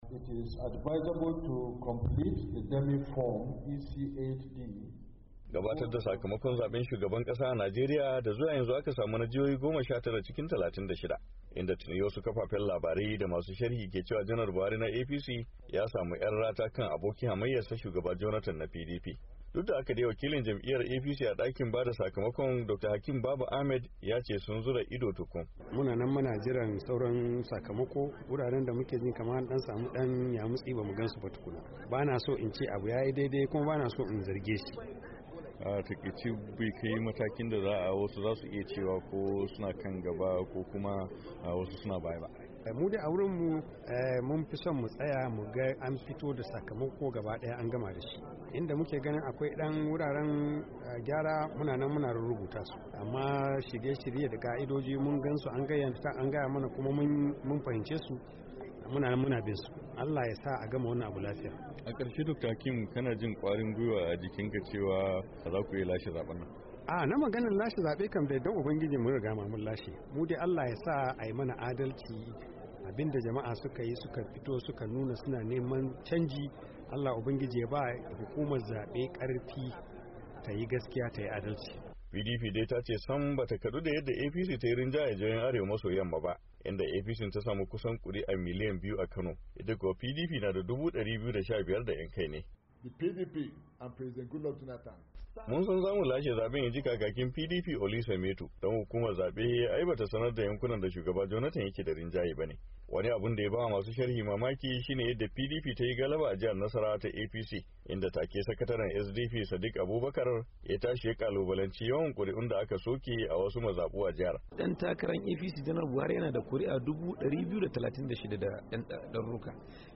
Ga rahoton